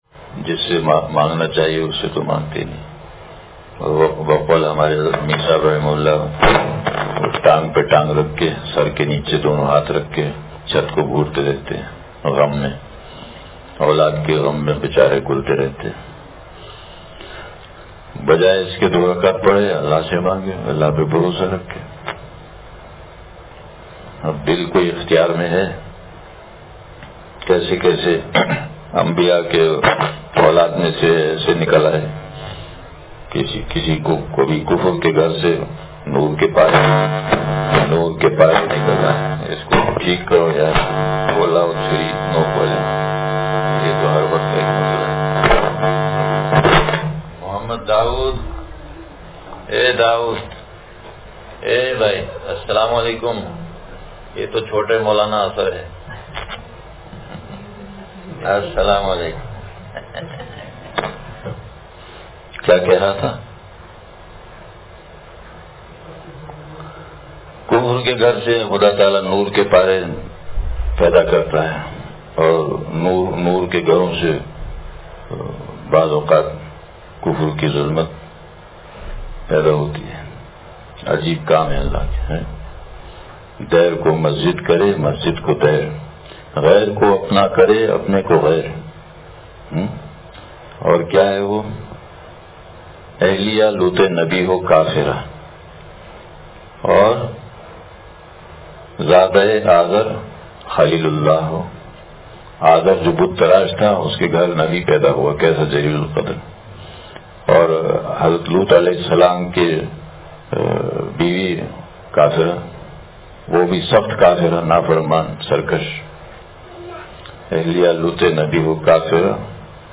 خاک سمجھا تھا جسے لعلِ بدخشاں نکلا – مجلس بروز اتوار